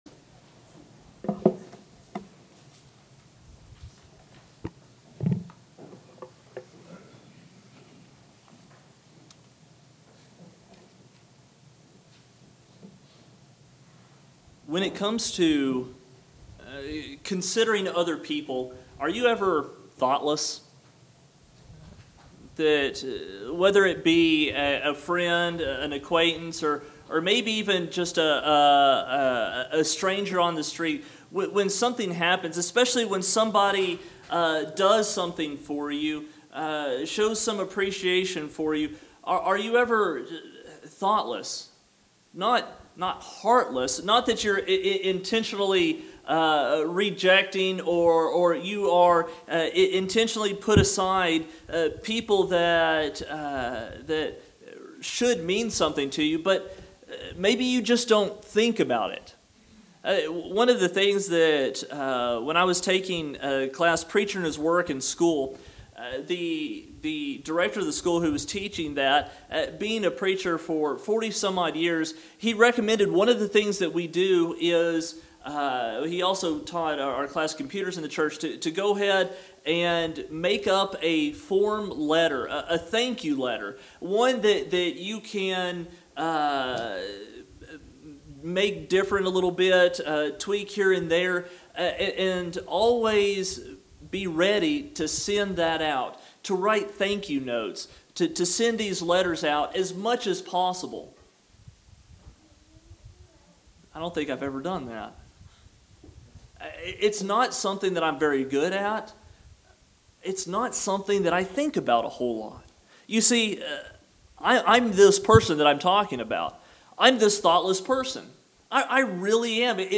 Sermon on Valuing our Christian Family